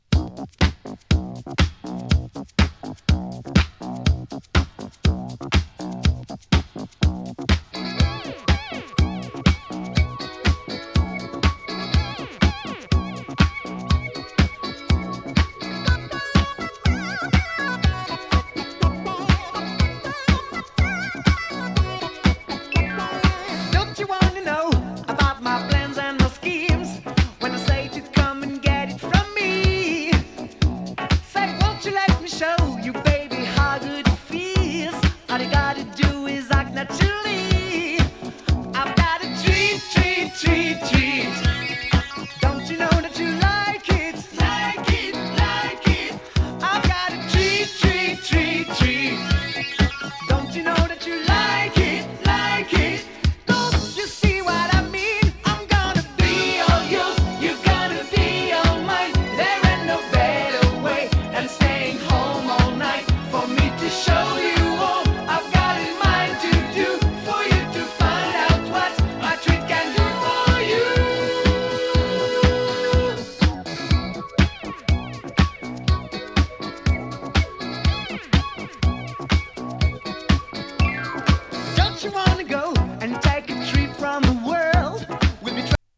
フレンチ ディスコ